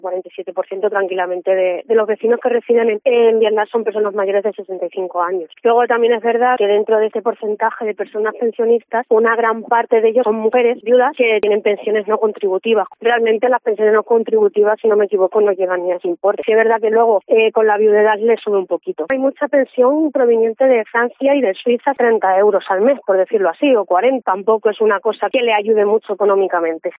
Casi la mitad de los vecinos de este pueblo de La Vera de 220 habitantes son pensionistas; aunque su realidad es todavía más complicada relata en nuestros micrófonos, Anabela Díaz, la alcaldesa del municipio, que asegura que la renta de la mayoría de estos es inferior, solo que se complementa con pensiones de viudedad y otras de países como Francia o Suiza, dónde los beneficiarios emigraron en el pasado.